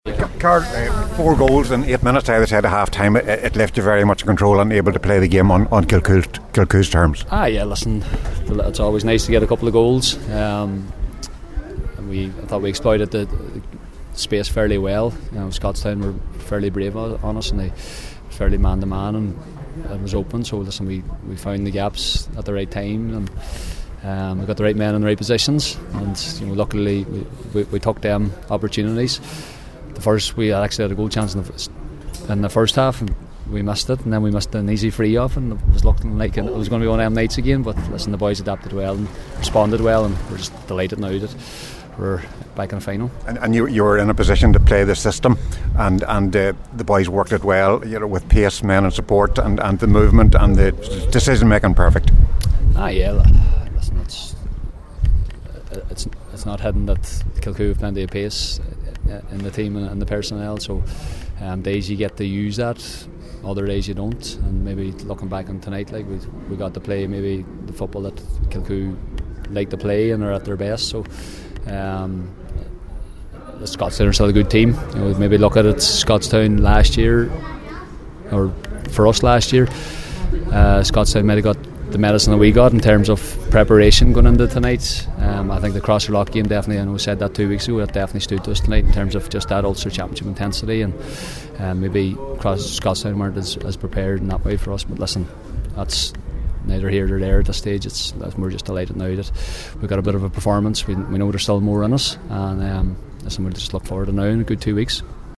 spoke with Lacey who was “delighted to reach the final”…